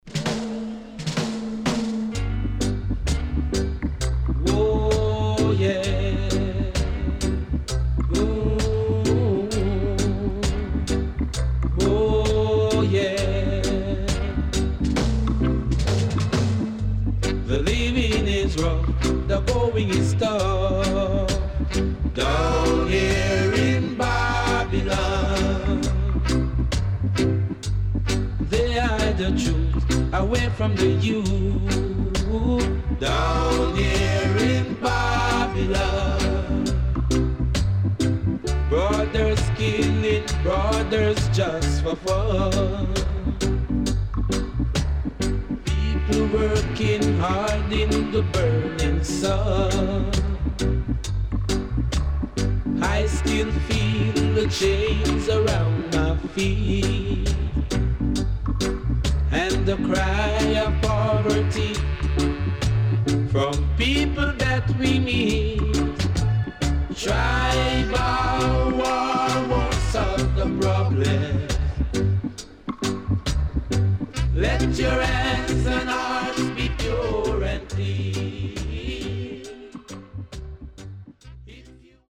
名曲.Wicked Roots Anthem & Dubwise